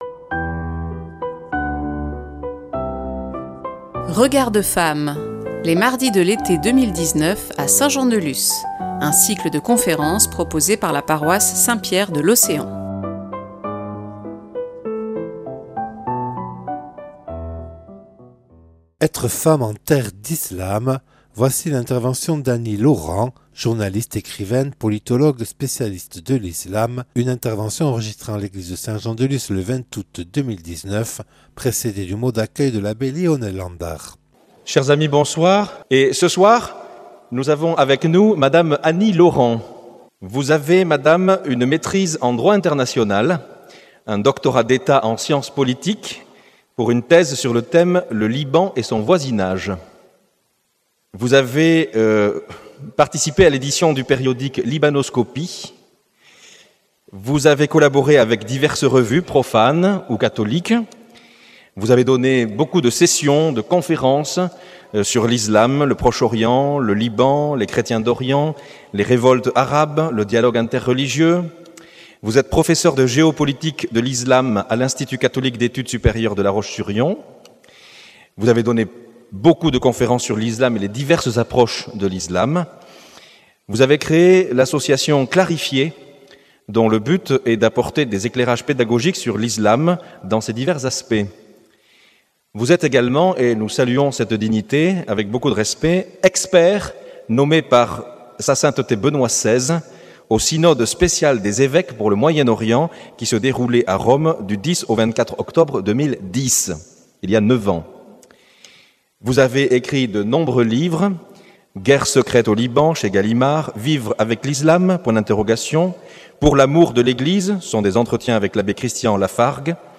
(Enregistré le 20/08/2019 en l’église de Saint-Jean-de-Luz lors des mardis de l’été proposés par la Paroisse Saint Pierre de l’océan).